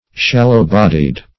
Search Result for " shallow-bodied" : The Collaborative International Dictionary of English v.0.48: Shallow-bodied \Shal"low-bod`ied\, a. (Naut.) Having a moderate depth of hold; -- said of a vessel.
shallow-bodied.mp3